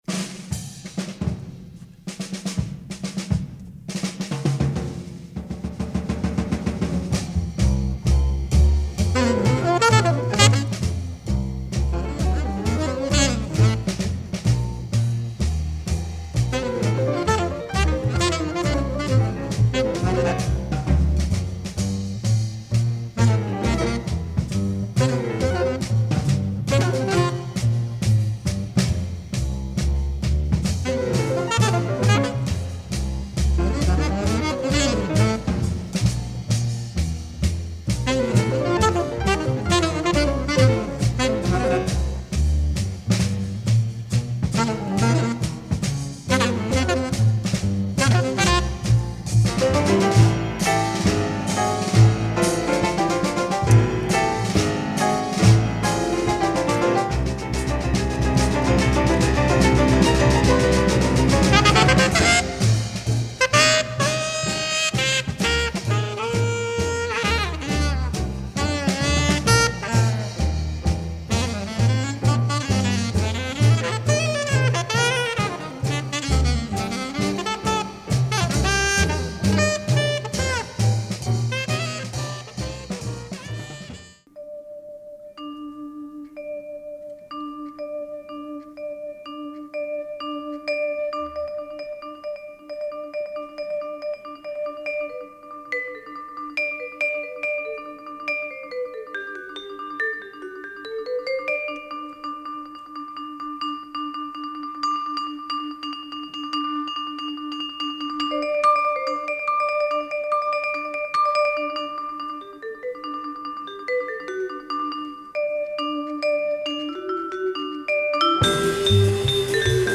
jazz quintet
influenced by music from Azerbaijan.